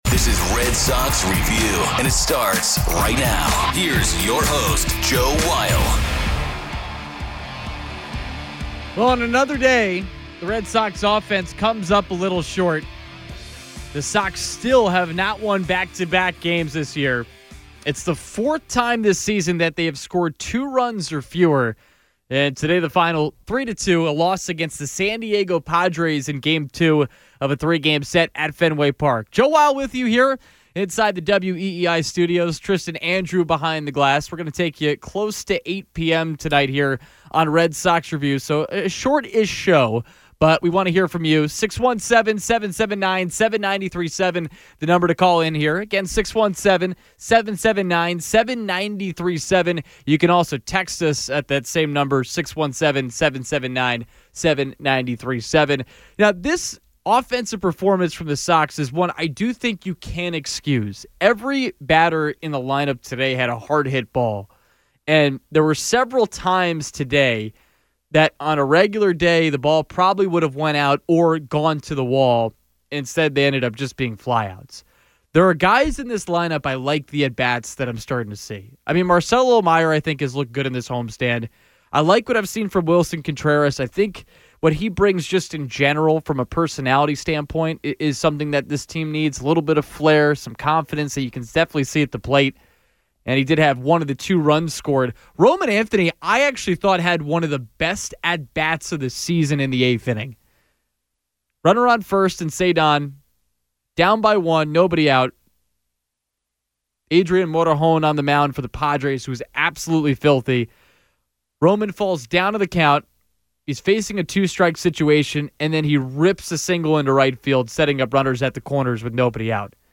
Boston Baseball